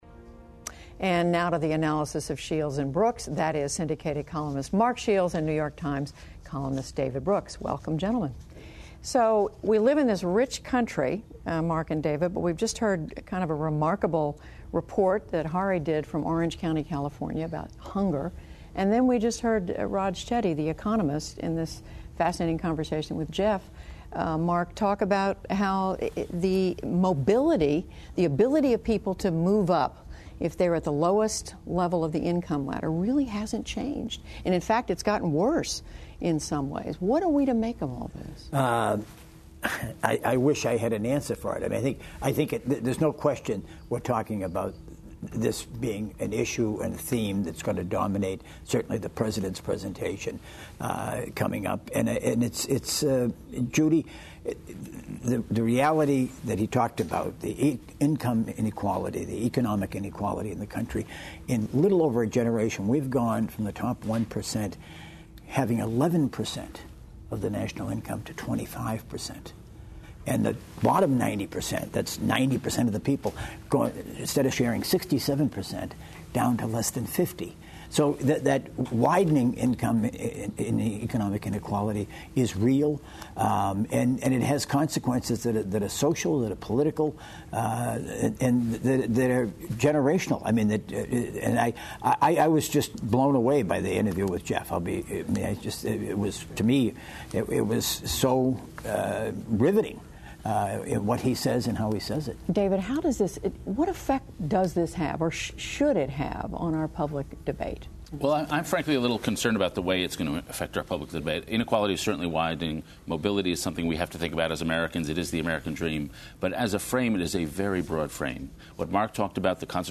JUDY WOODRUFF: And now to the analysis of Shields and Brooks. That's syndicated columnist Mark Shields and New York Times columnist David Brooks.